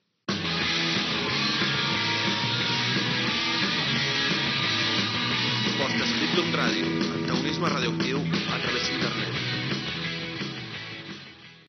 Indicatiu de la ràdio a Internet.